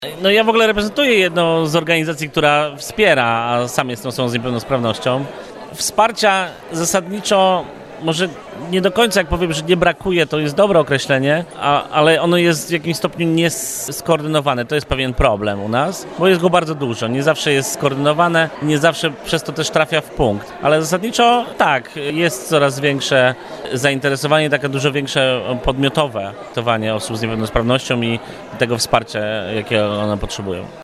W auli UZ przy ul. Wojska Polskiego zaprezentowały się stowarzyszenia i instytucje, które na co dzień zajmują się osobami niepełnosprawnymi.